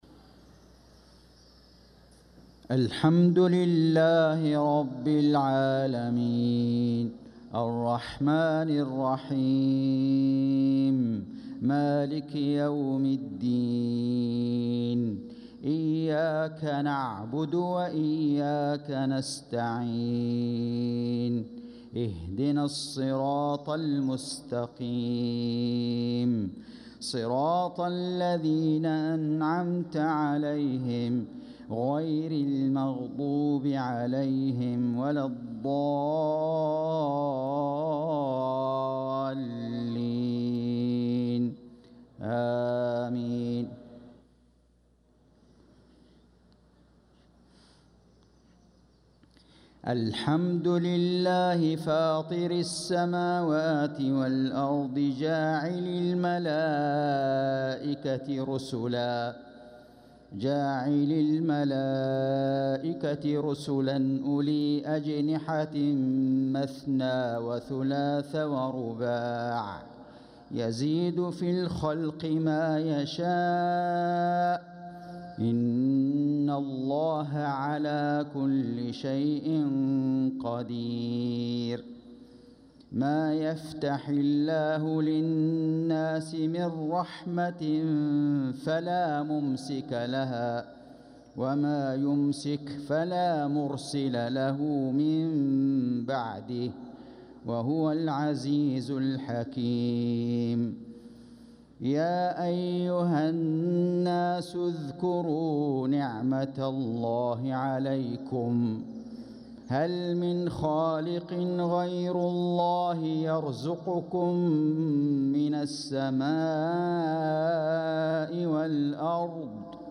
صلاة المغرب للقارئ فيصل غزاوي 14 ربيع الآخر 1446 هـ